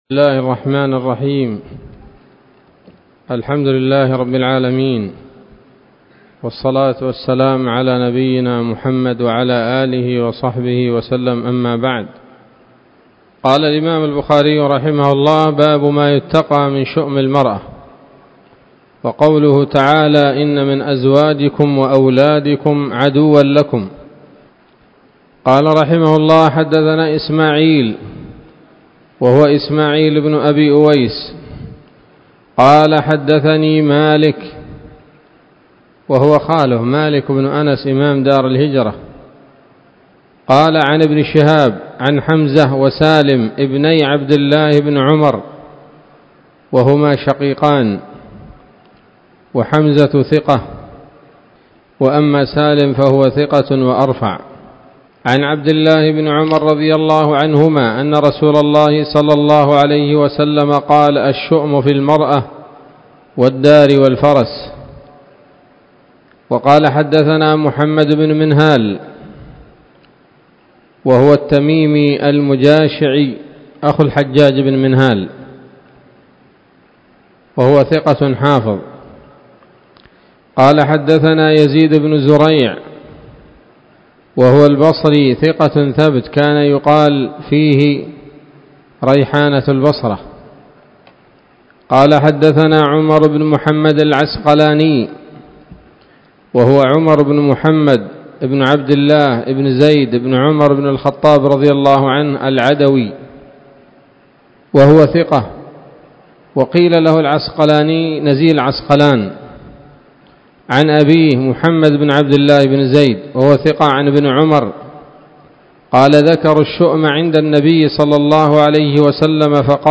الدرس السابع عشر من كتاب النكاح من صحيح الإمام البخاري